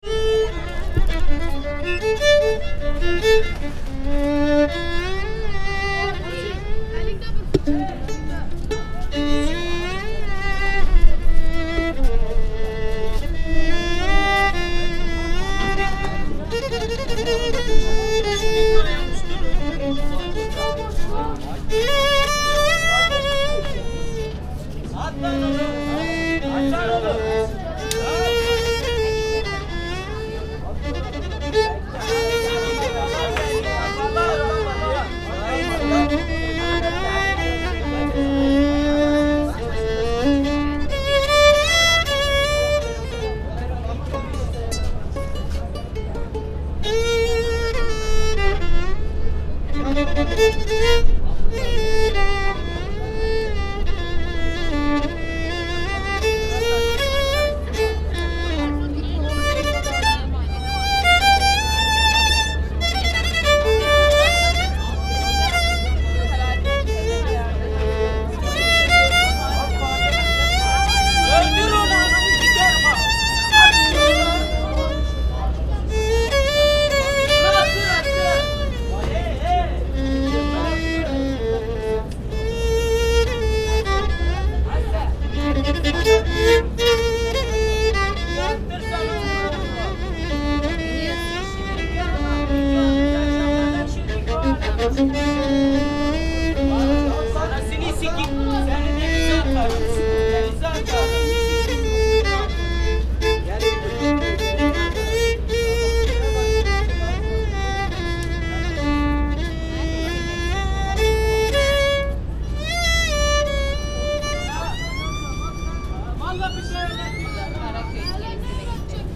Nomad violinist on the streets of Istanbul
After interviewing a violinist from the nomad cultures of eastern Turkey, he offered to play to me. His virtuosity and feeling were incredible, especially since a malformation in his wrist had forced him to develop a new style of playing with the violin pointing down to his lap.
Part of the Migration Sounds project, the world’s first collection of the sounds of human migration.